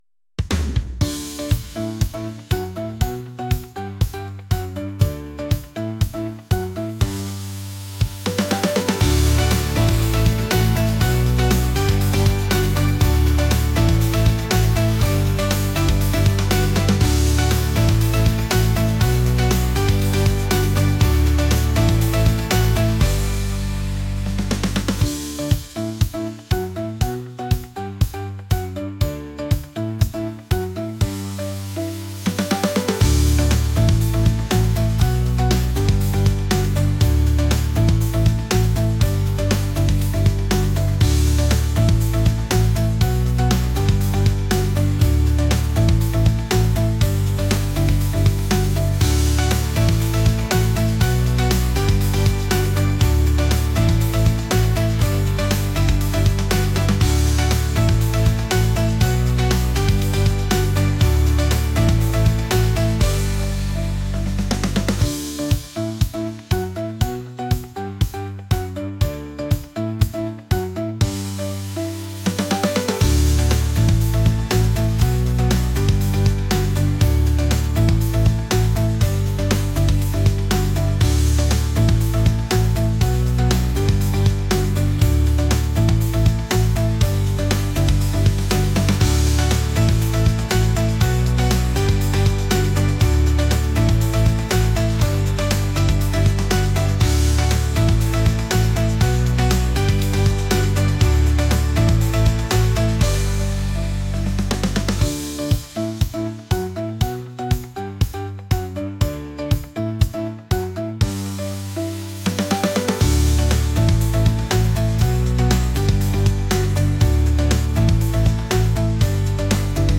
pop | catchy | energetic